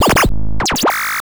Glitch FX 19.wav